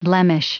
Prononciation du mot blemish en anglais (fichier audio)
Prononciation du mot : blemish